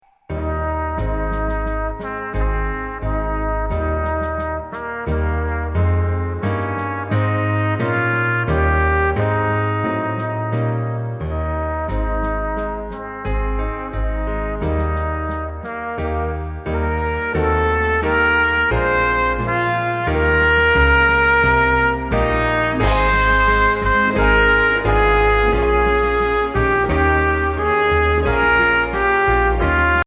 Voicing: Tenor Saxophone